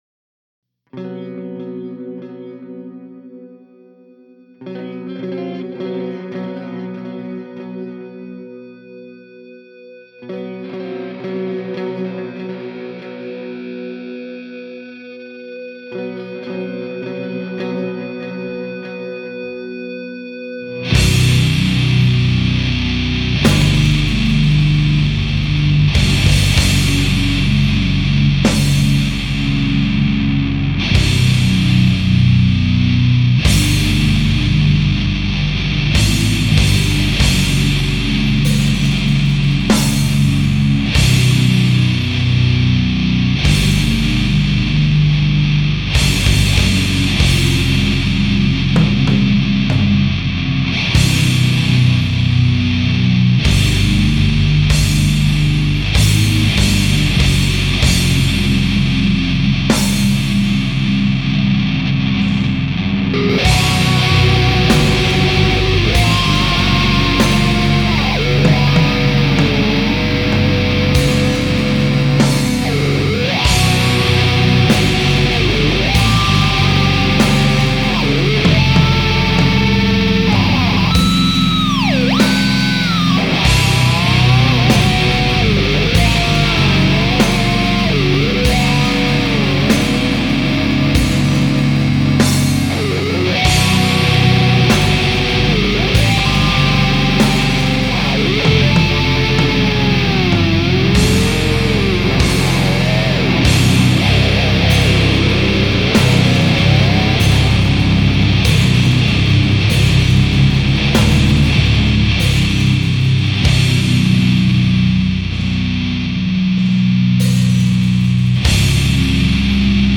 ドゥームアレンジ。